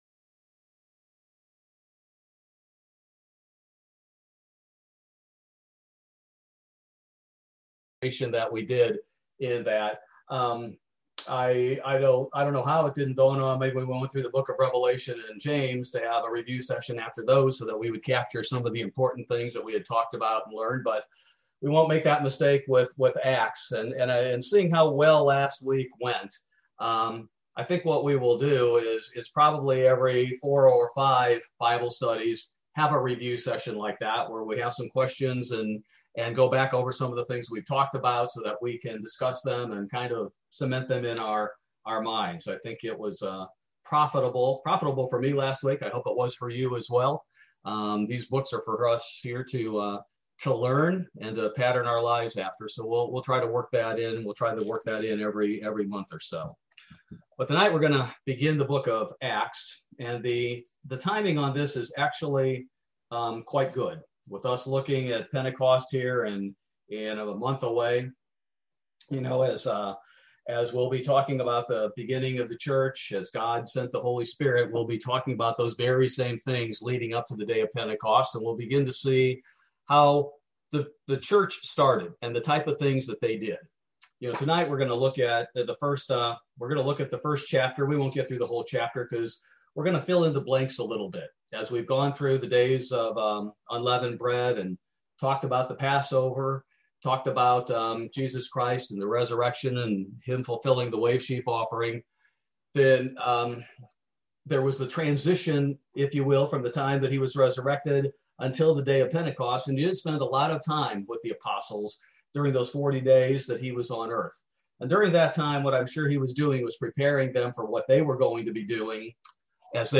Bible Study: April 14, 2021